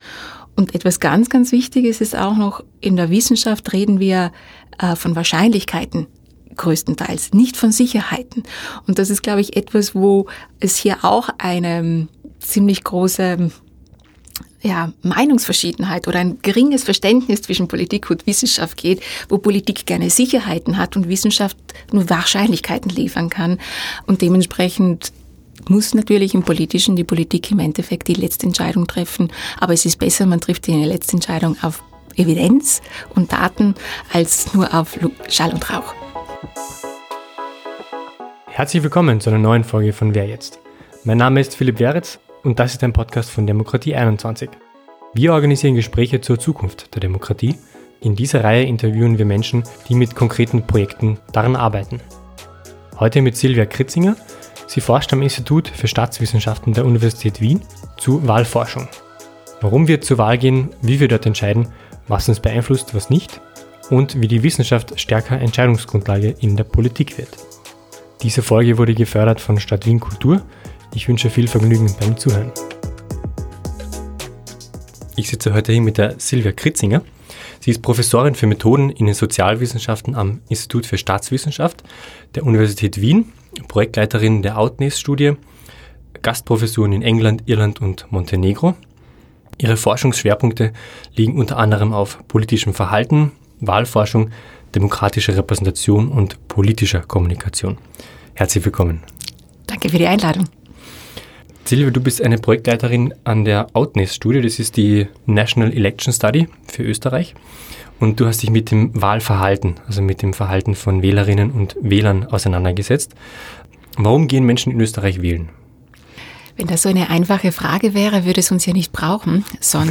Warum Wahlverhalten komplexer ist als angenommen, was heiraten damit zu tun hat und warum Familie in Österreich wieder an Wichtigkeit gewinnt. Lesen Sie hier zwei Auszüge aus dem Gespräch.